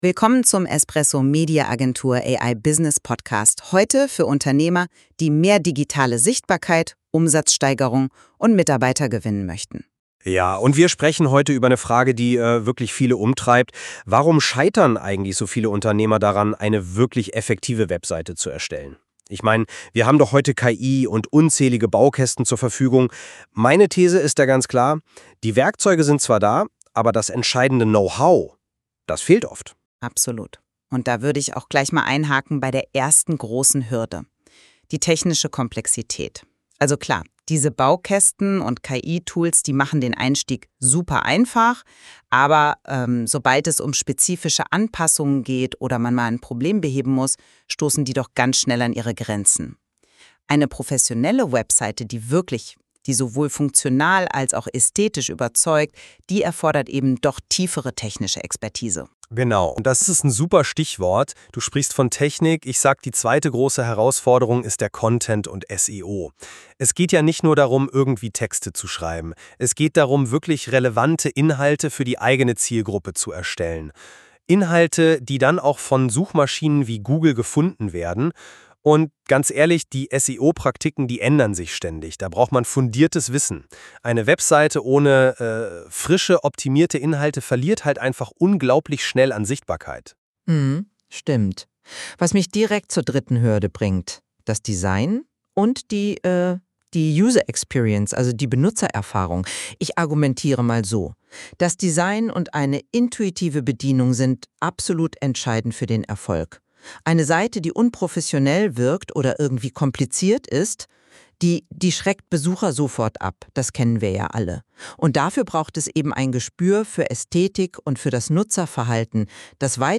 espresso media agentur – AI Podcast: